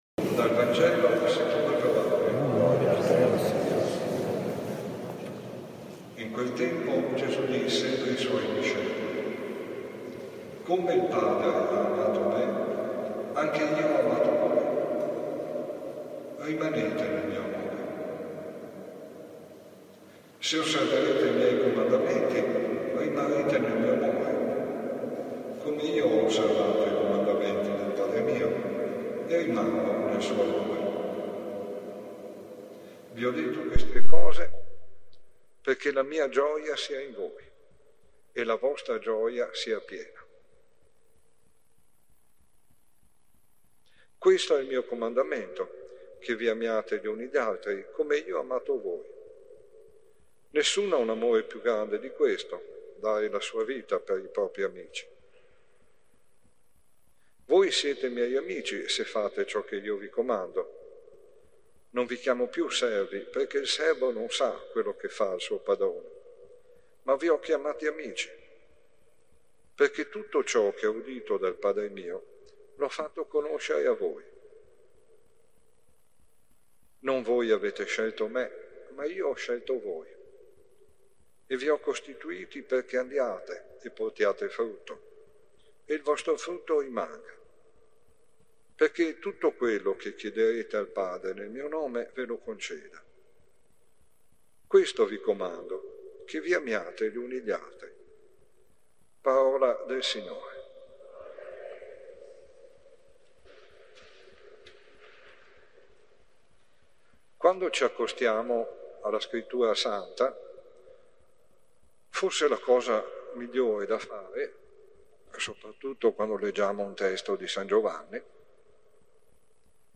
Omelie Giornaliere - CappellaDellaSapienza